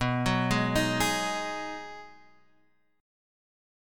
B6 Chord